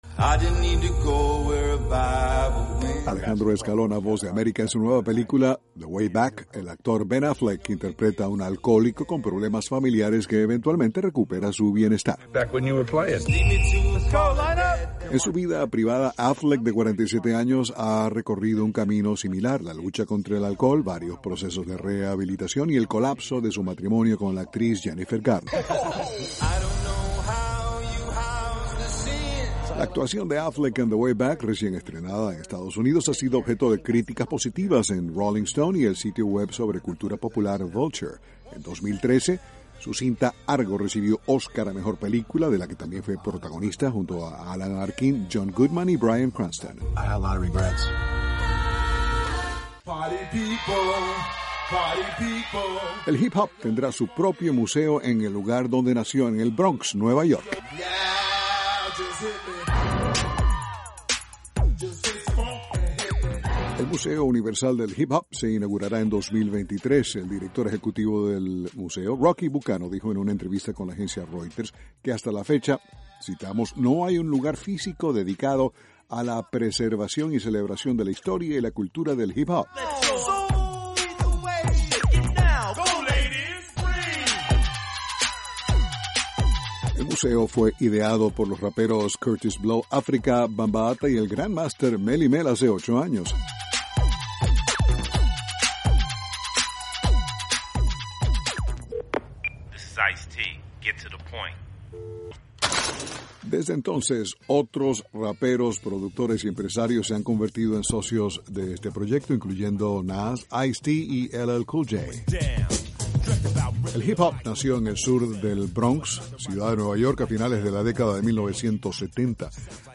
informa desde Washington...